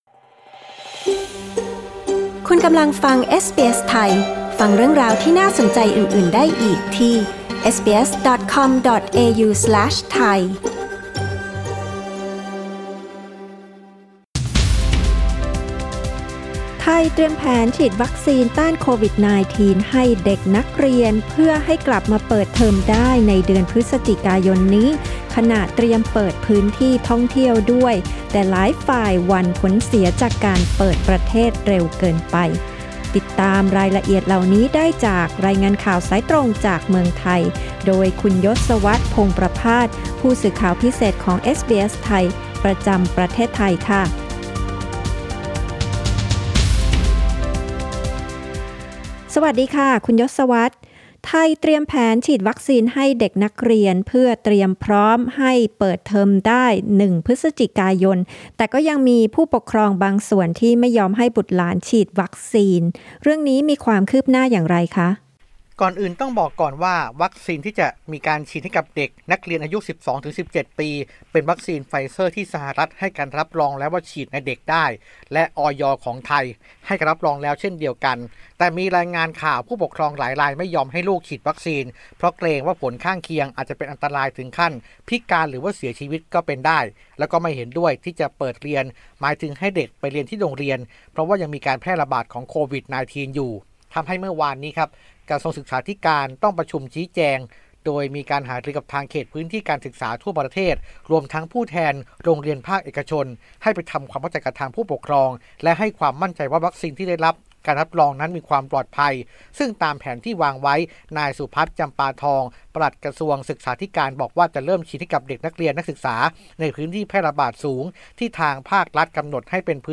ฟังรายงานข่าว